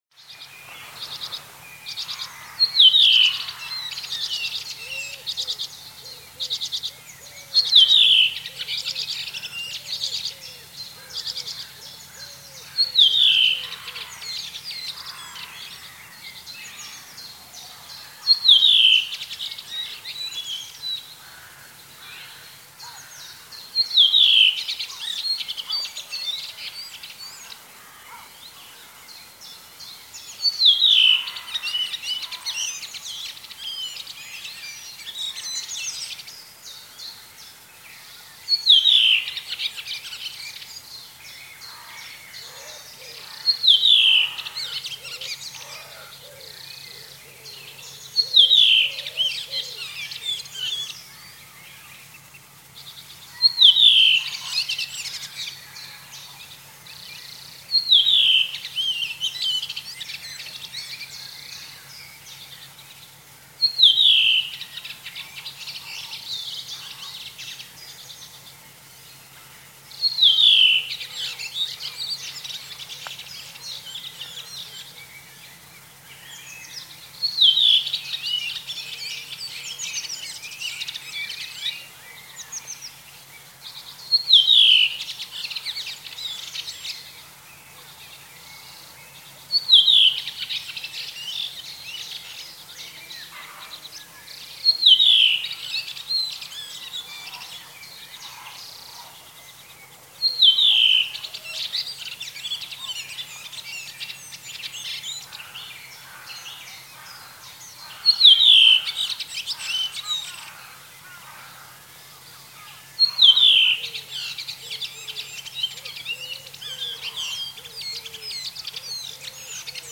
Redwing song Rødvingetrost sang Rotdrossel sound effects free download